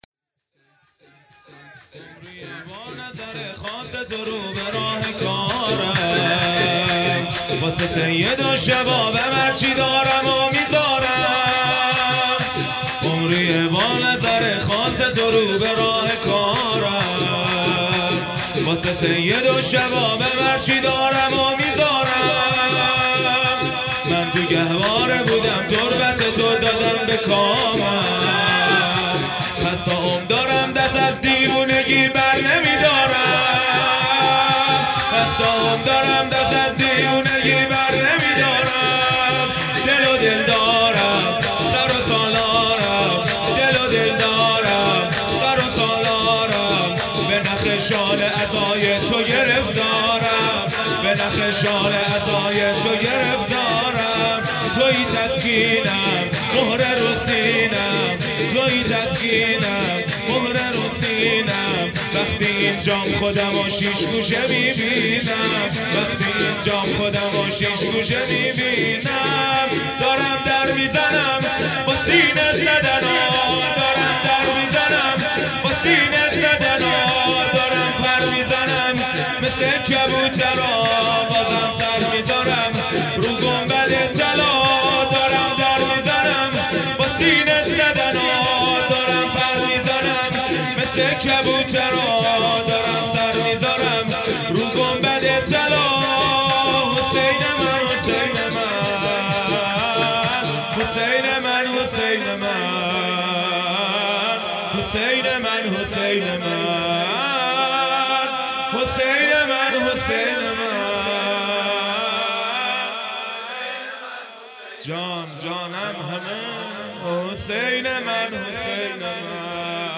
جلسه هفتگی|18 مهر 96